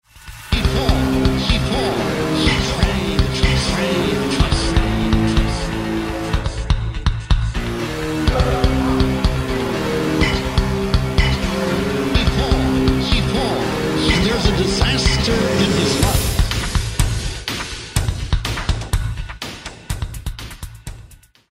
The dark evil low pad sound used during